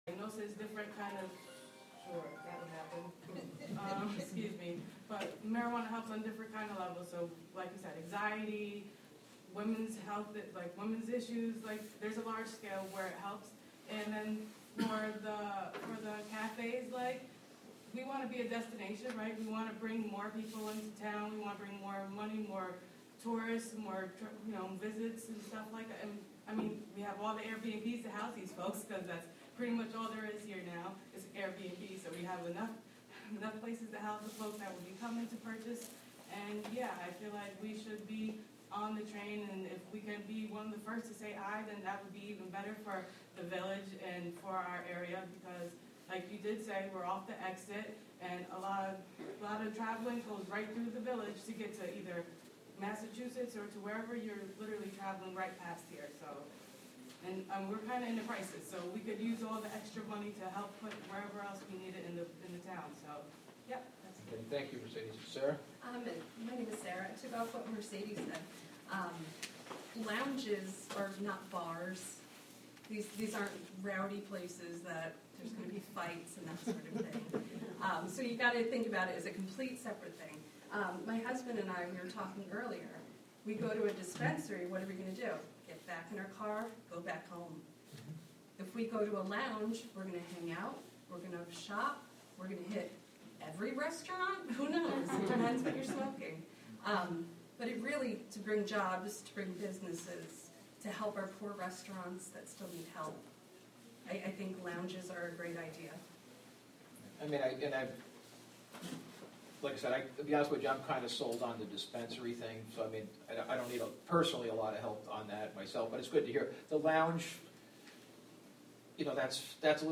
Live from the Town of Catskill: Town Board Committee Meeting November 17, 2021 (Audio)